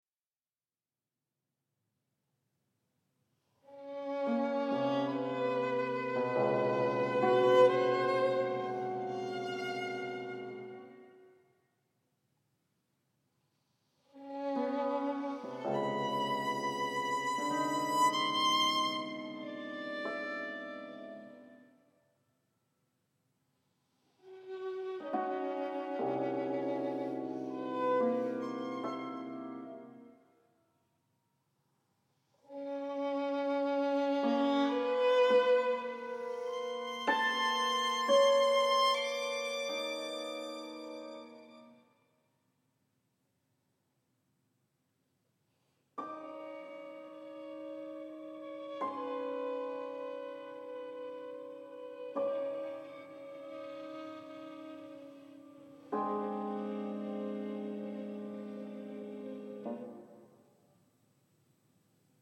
for violin and cimbalom